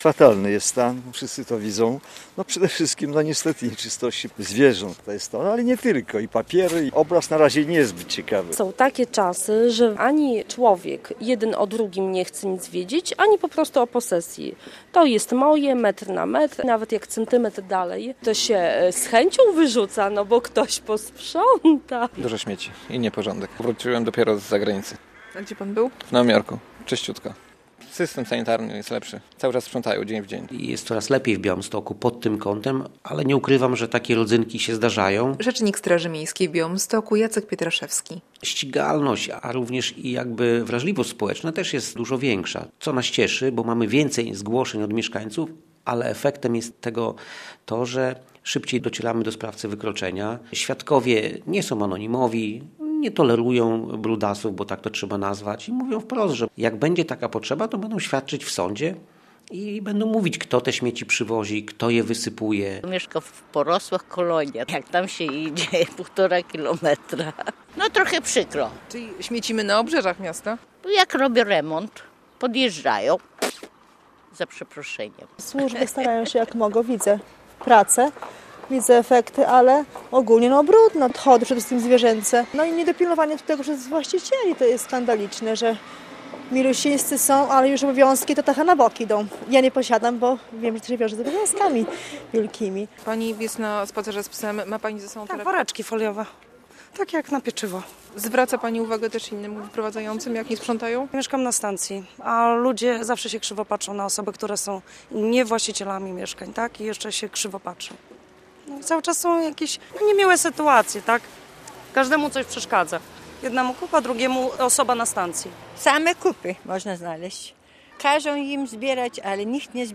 Sprzątanie po zimie w reporterskiej relacji